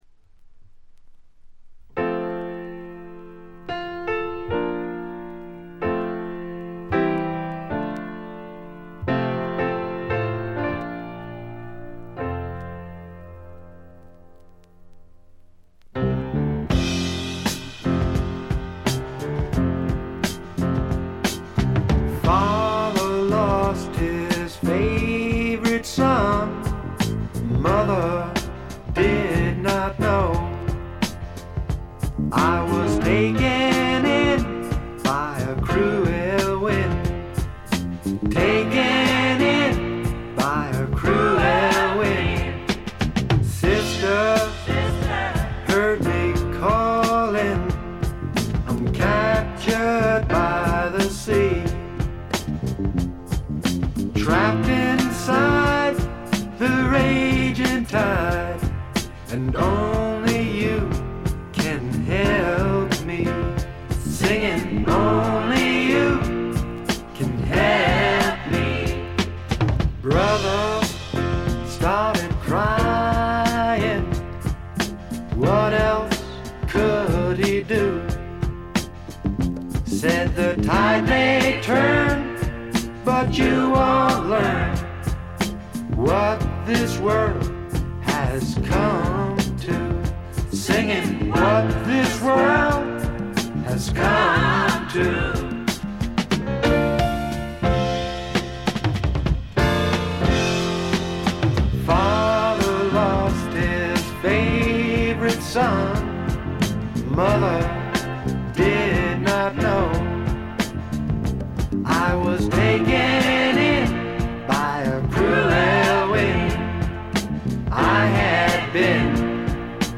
ほとんどノイズ感無し。
素晴らしい楽曲と素朴なヴォーカル。フォーキーな曲から軽いスワンプ風味を漂わせる曲までよく練られたアレンジもよいです。
試聴曲は現品からの取り込み音源です。
Vocals, Piano, Harmonica, Acoustic Guitar